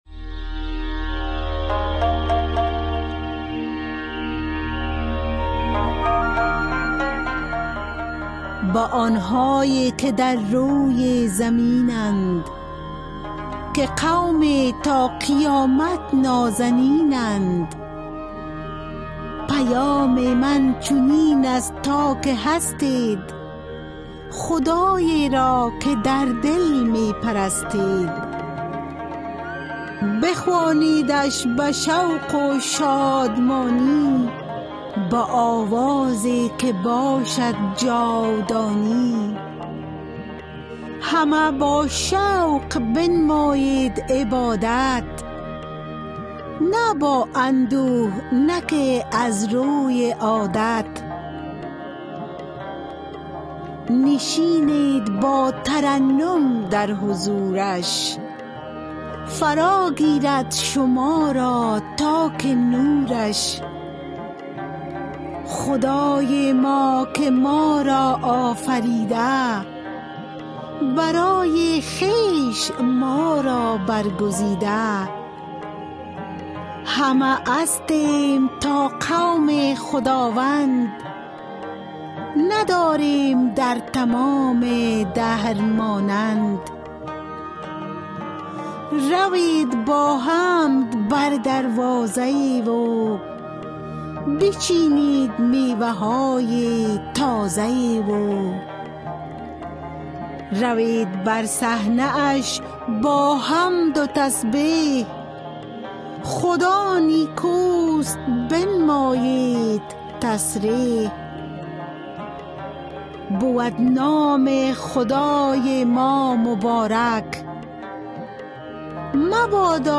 Poem Psalm 100